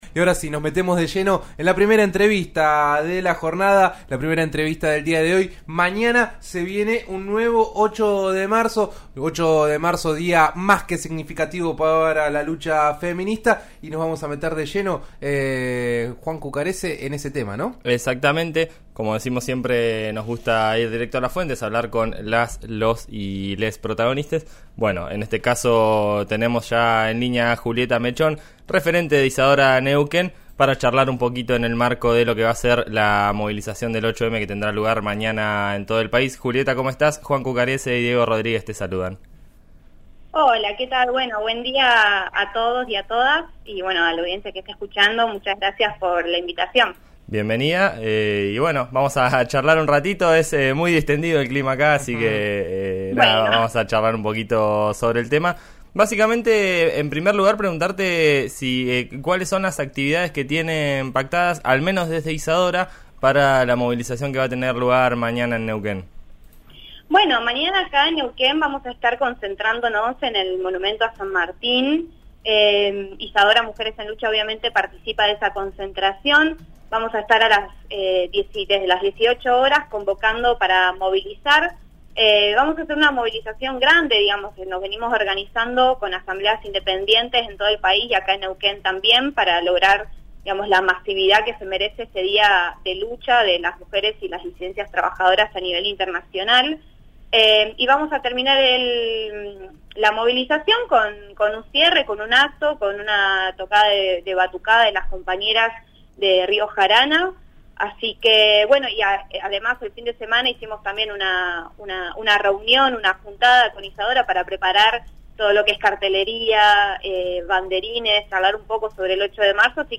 habló al aire de En Eso Estamos por RN Radio (FM 89.3) y comentó que 'nosotras no somos de quedarnos en casa